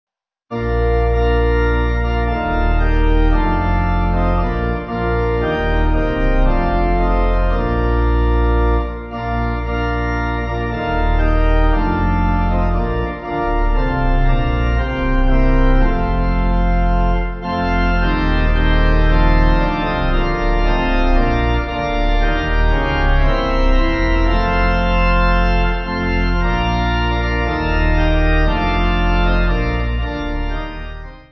7.6.7.6.D
(CM)   3/Bb